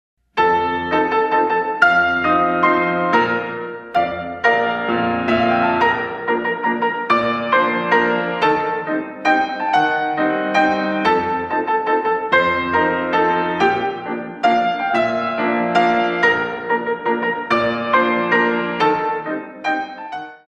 32 Counts
Grand Battement